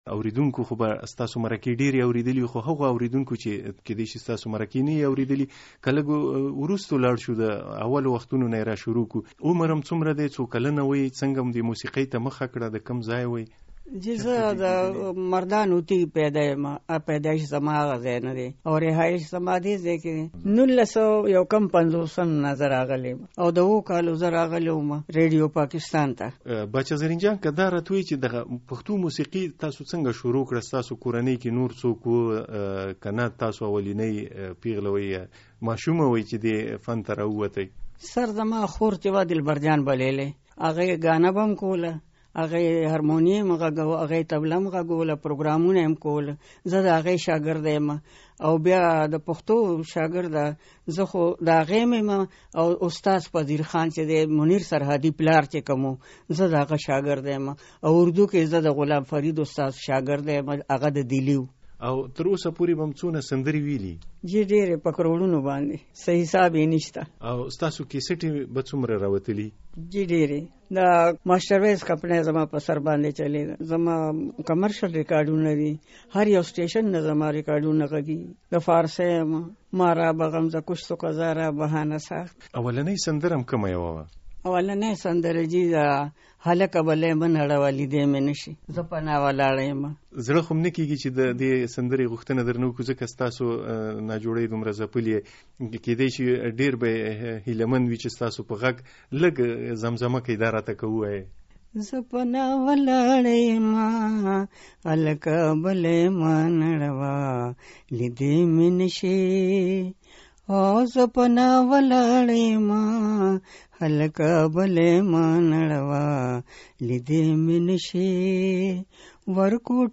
د باچا زرين جان سره مرکه